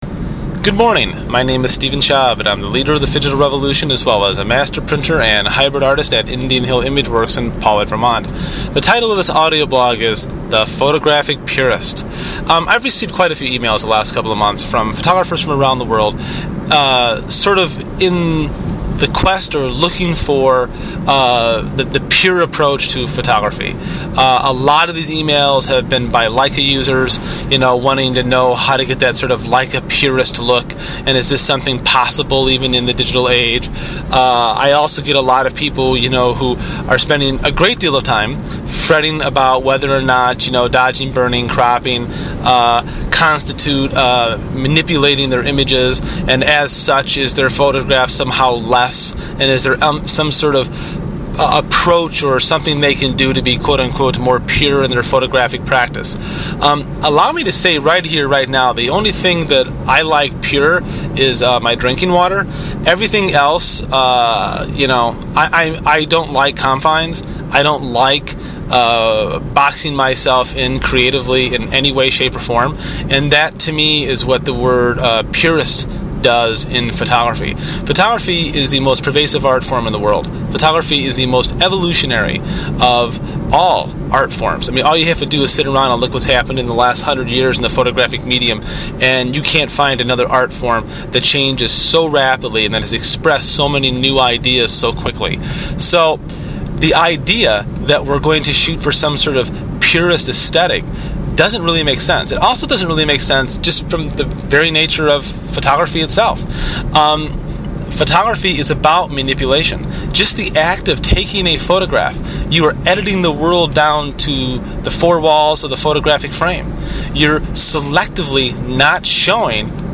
This audio blog explores this concept and the damages it is causing to photographers everywhere.